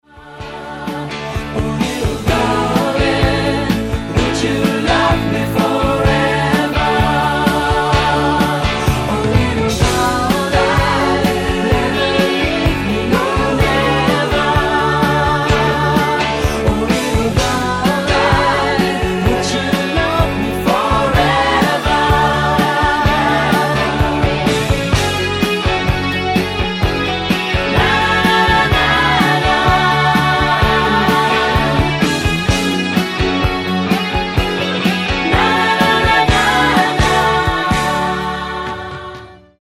LightMellow